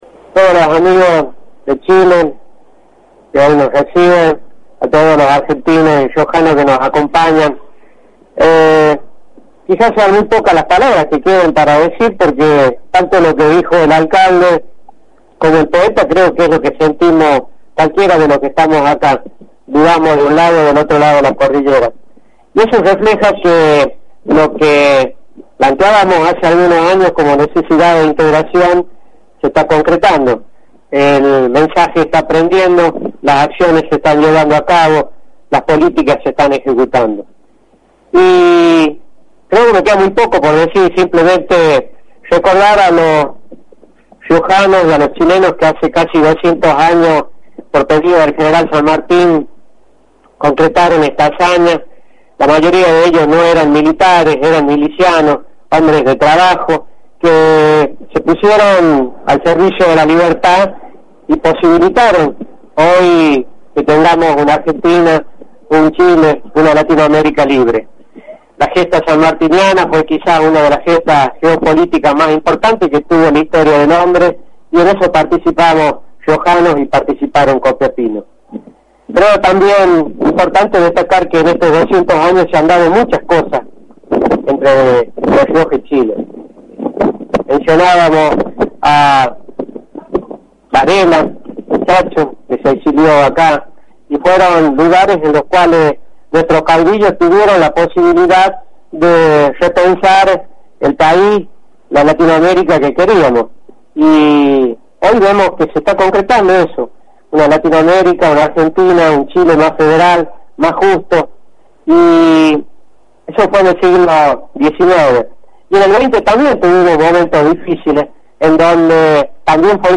Flores habló durante el acto central en nombre del Gobierno al valorar que una escuela de Copiapó lleve el nombre de La Rioja y por eso, se le destinarán equipamiento técnico argentino.
Flores habló en nombre del Gobierno en Copiapó
walter-flores.mp3